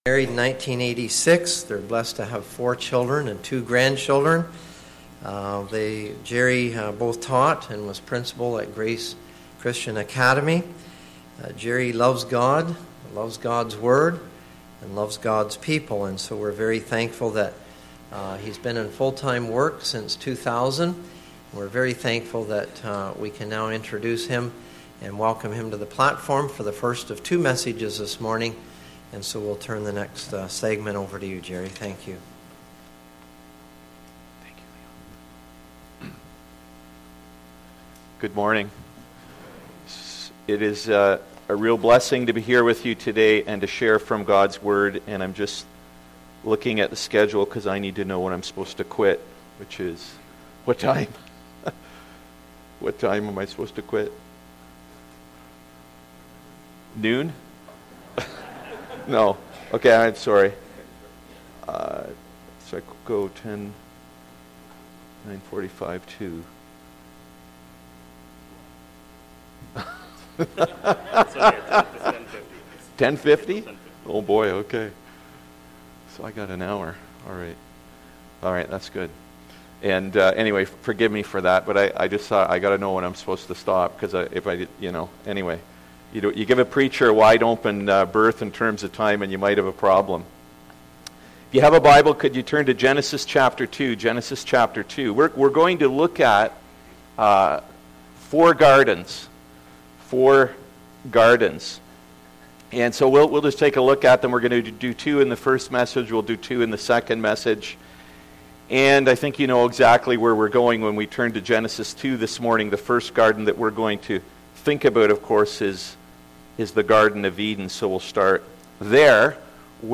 Good Friday Service…Four Gardens Part 1